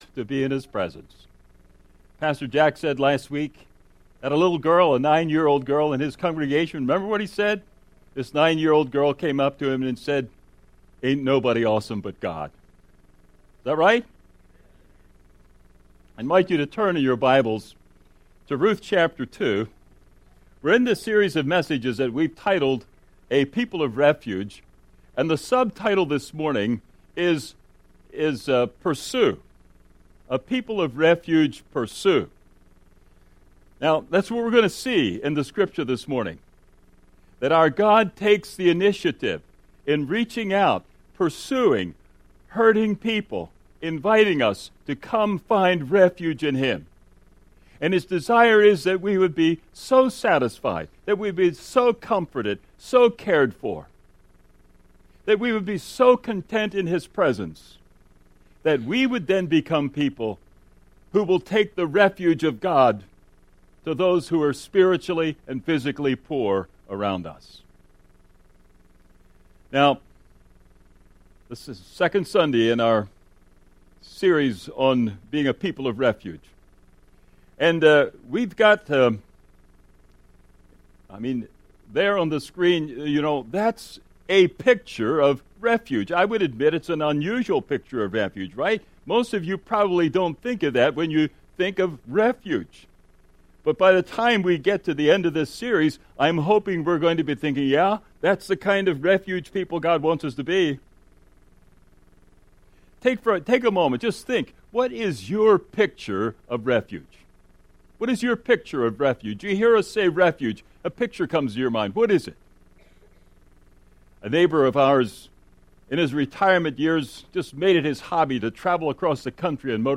11-9-14-sermon.mp3